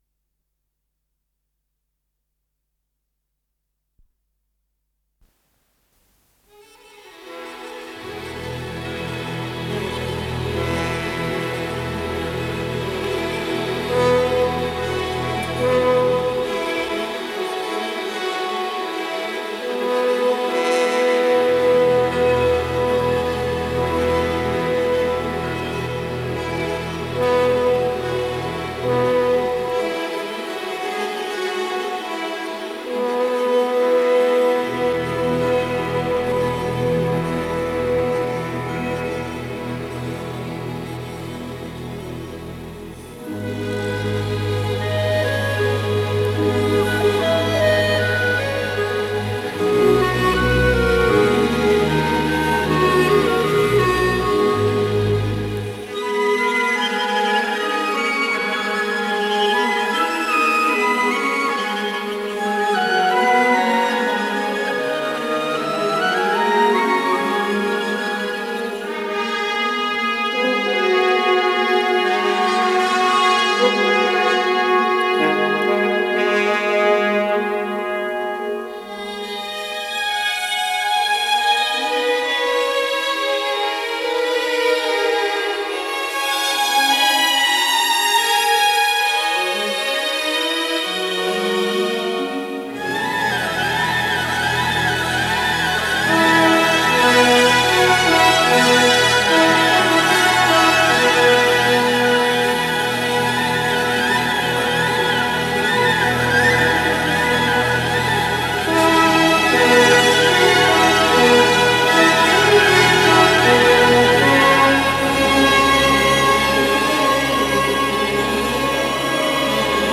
пьеса для оркестра
ВариантДубль моно